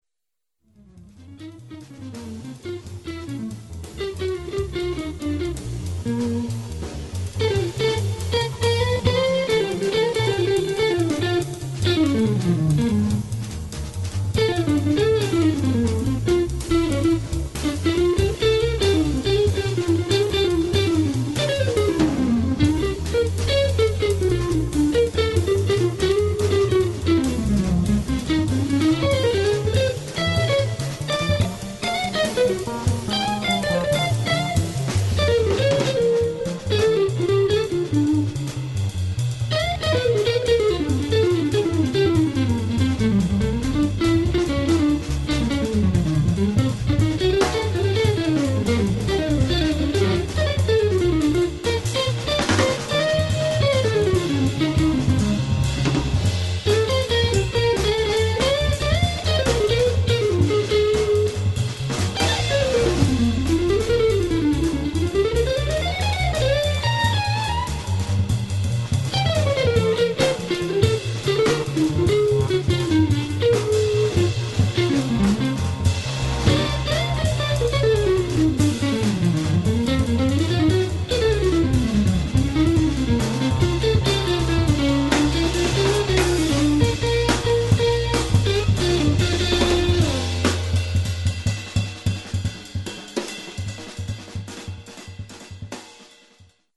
trio jazzowego